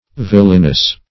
Villenous \Vil"len*ous\, a. Of or pertaining to a villein.